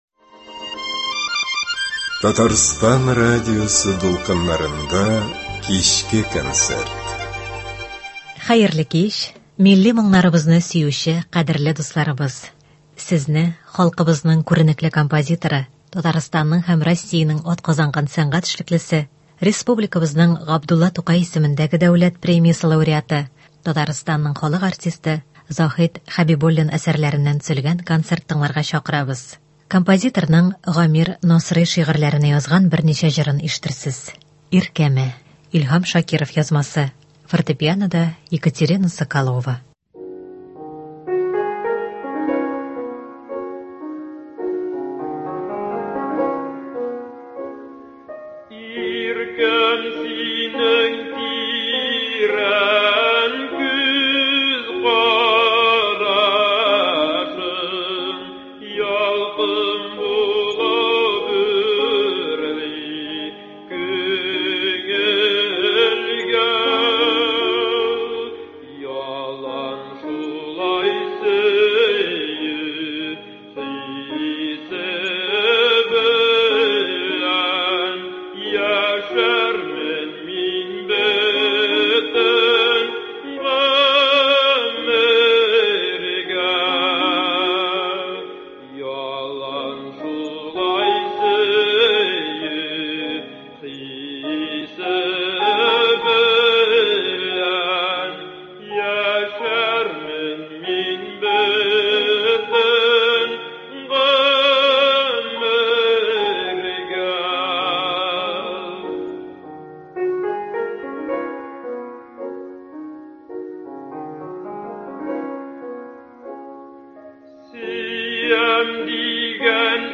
Көндезге концерт.
Кичке концерт.